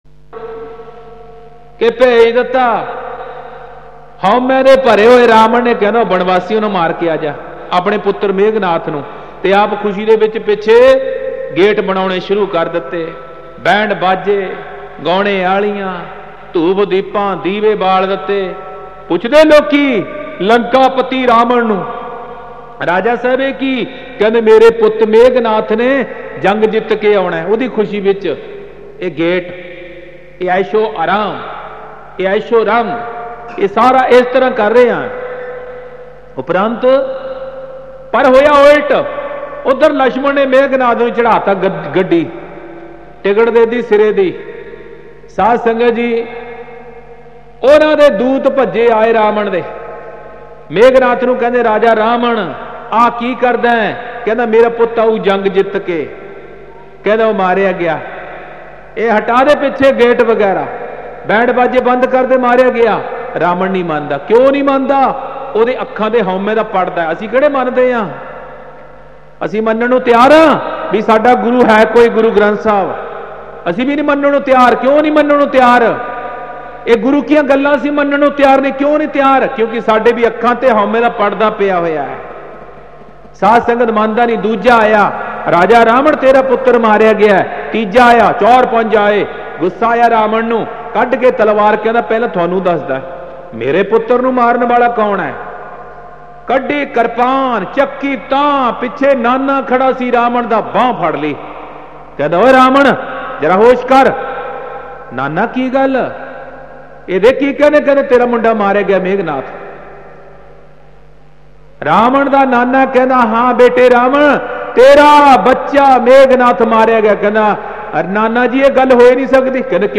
Genre: Gurmat Vichaar(Katha)